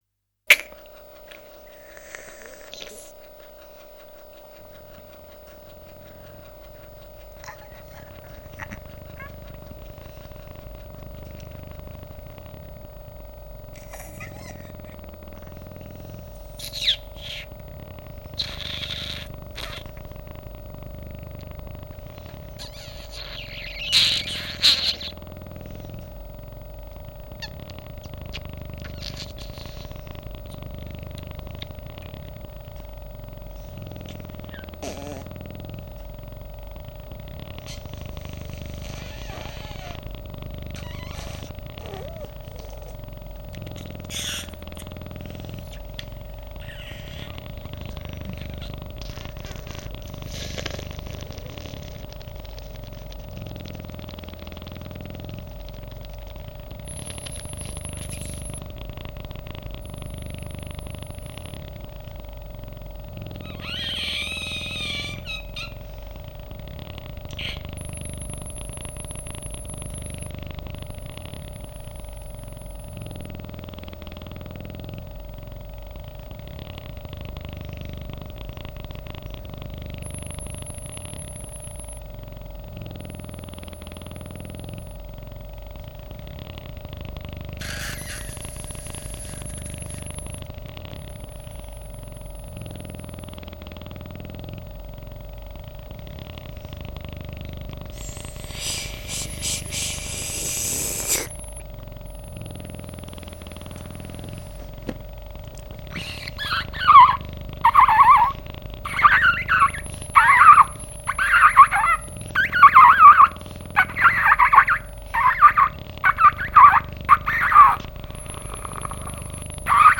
clarinet